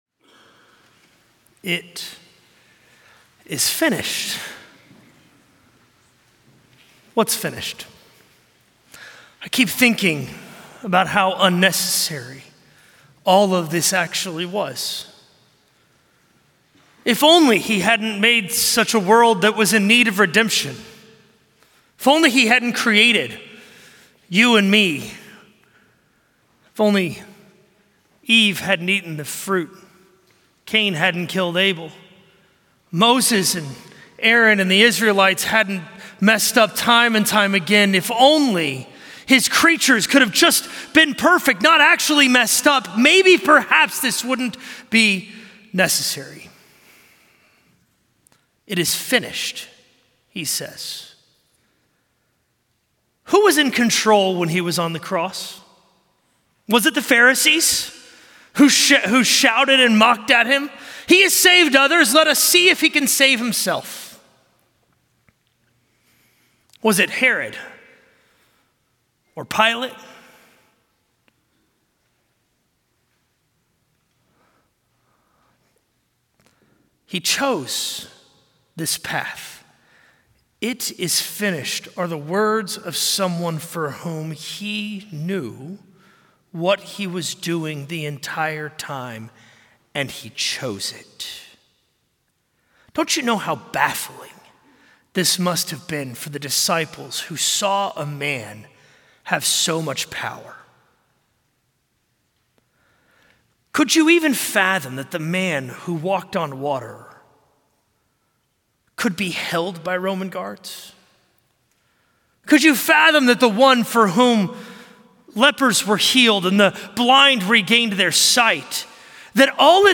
A message from the series "Easter 2025."